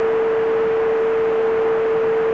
сигнал 7418 DE1103,"narrow".